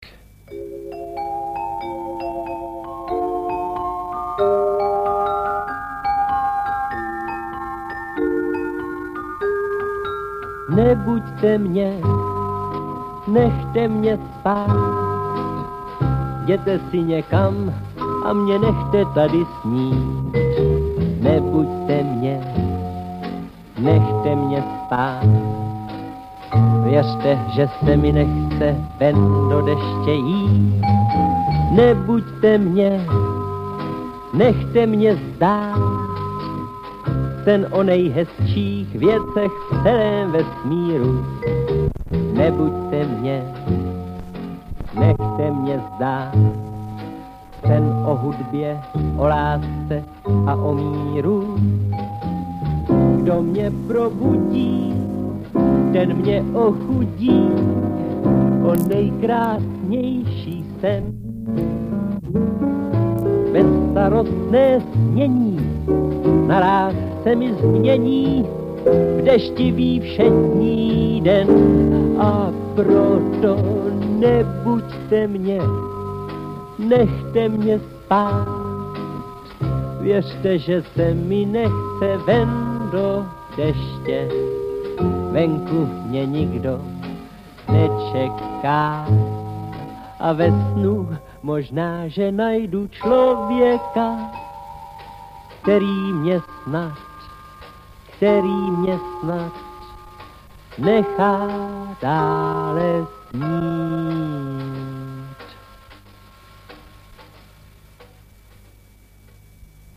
Z relace Rozhlasu po drátě vysílaného z Městského národního výboru v Bruntále. Pořad byl s největší pravděpodobností věnován VII. ročníku Divadelního máje – Národní přehlídce amatérských divadelních souborů v Krnově. Záznam – fragment relace pochází z velmi poničené magnetofonové pásky z roku 1965.